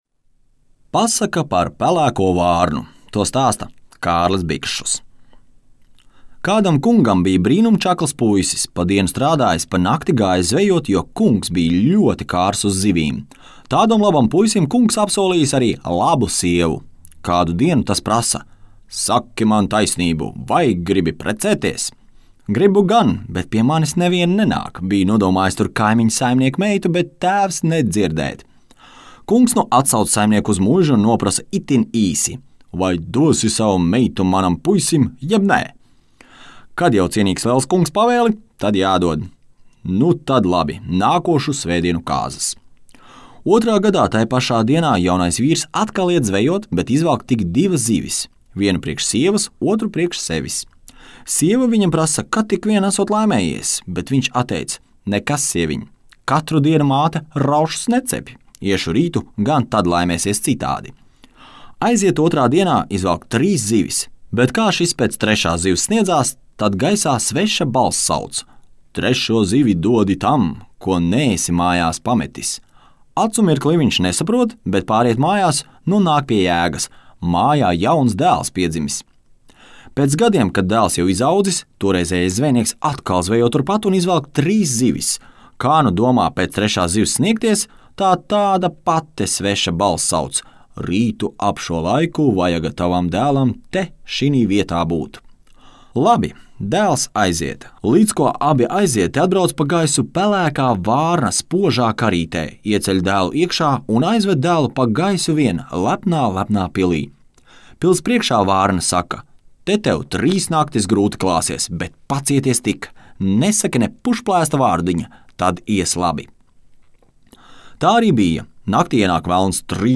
Teicējs